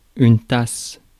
Ääntäminen
France: IPA: [yn tas]